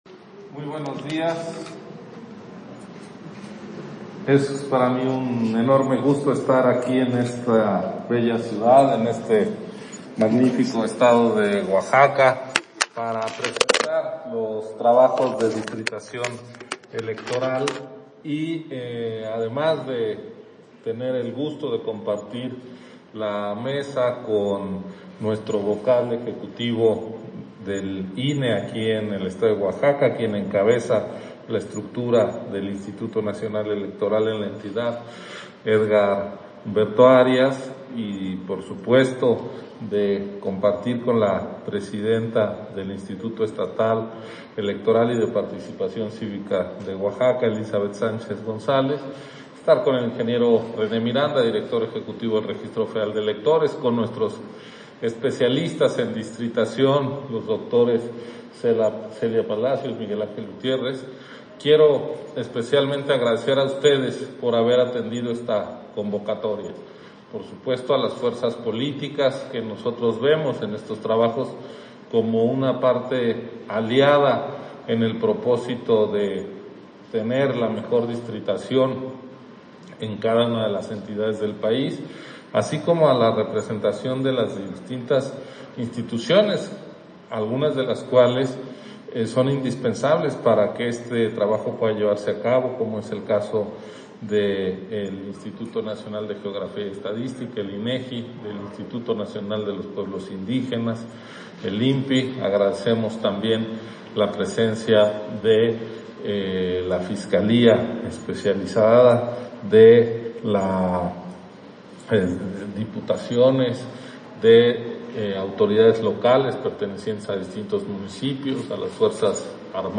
Intervención de Ciro Murayama, en el Foro Estatal de Distritación Nacional Electoral 2021-2023, Oaxaca